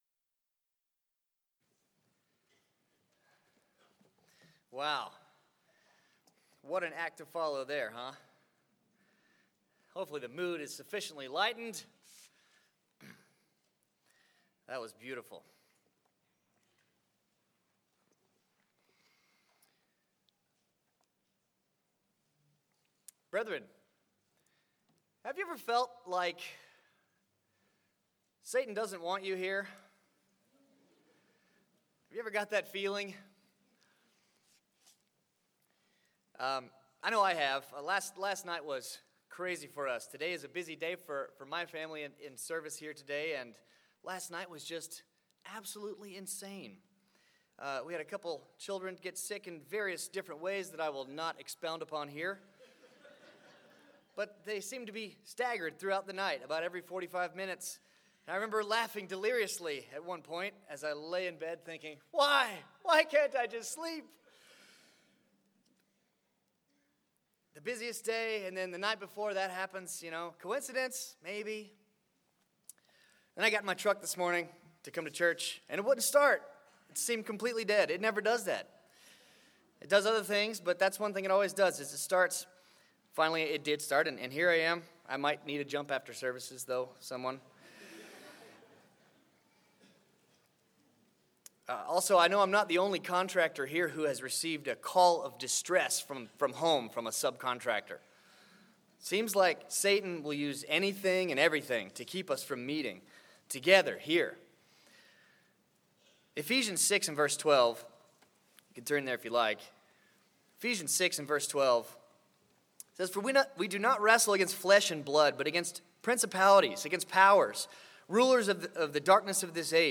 This sermon was given at the Bend-Redmond, Oregon 2019 Feast site.